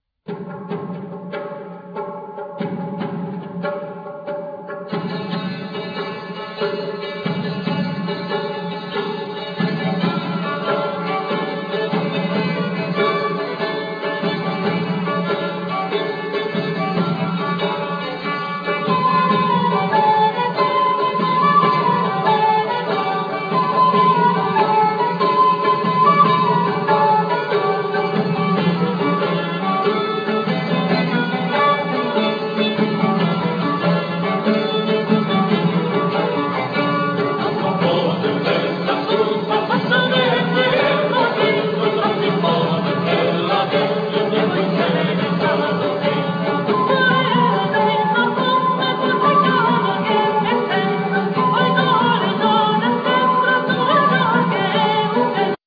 Vocal,Portative organ
Percussions
Bombarde,Shofar,Recorder,Santur,Vocal
Lute
Ud
Didjeridu,Shakuhachi,Jew's harp
Alto fiddle